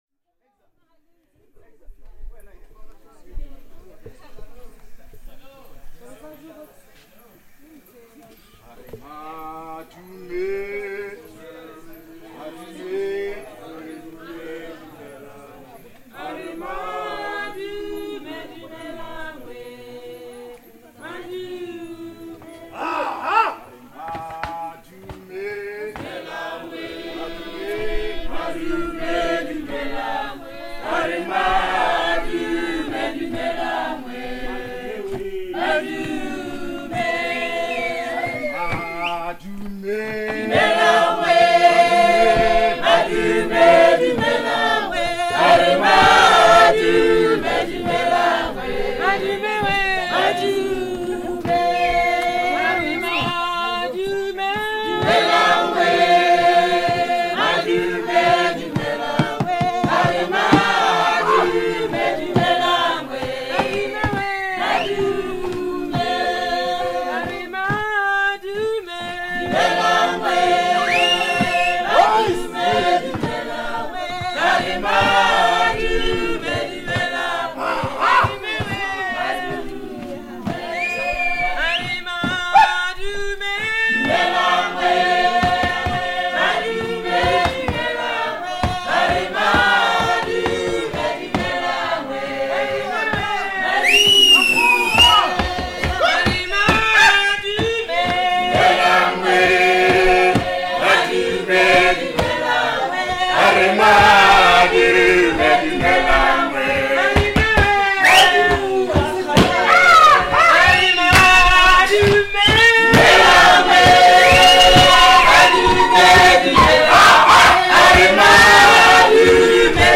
Recorded under the starry expanse of the Okavango Delta at Gomoti Plains Lodge in Botswana, this audio captures a rare and mesmerizing moment of cultural and natural heritage. Gathered around a crackling fire, the lodge's staff performs traditional songs for their guests passed down through generations of Bushmen and Okavango communities. Their voices rise and fall in harmony, interwoven with the ambient sounds of firewood crackling and the symphony of the delta's nocturnal wildlife.